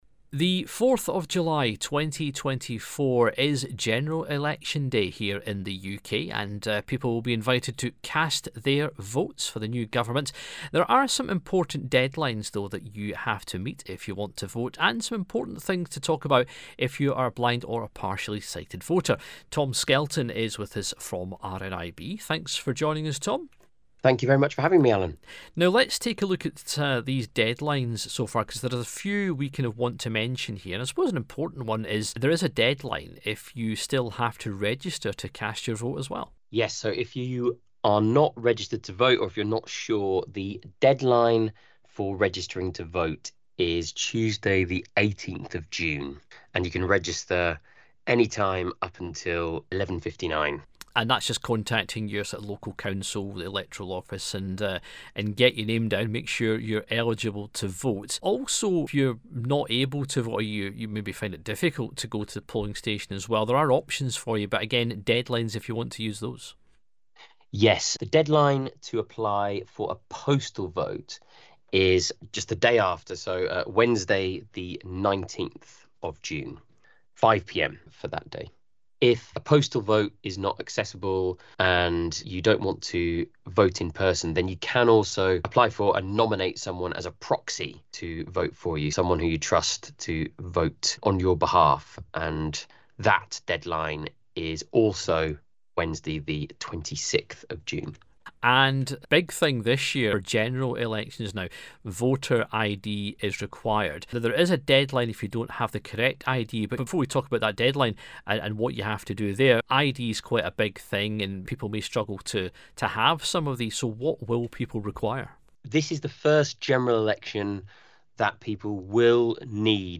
The deadlines mentioned in this interview have now passed, if you have registered to vote, remember to bring a photo ID or your Voter Authority Certificate if voting in England, Scotland, and Wales, or your Electoral ID Card if voting in Northern Ireland.